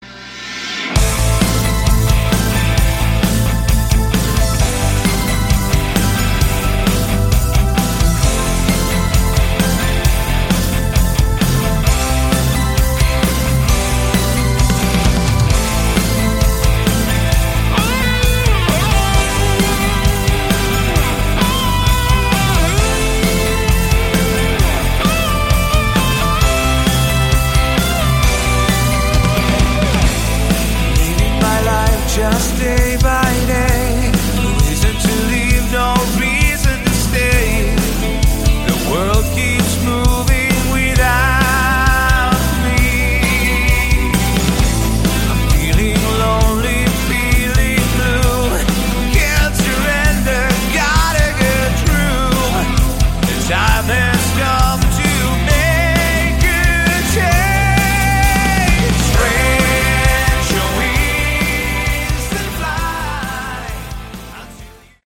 Category: Melodic Rock
drums, vst-bass, keyboards and backing vocals
lead and backing vocals
guitars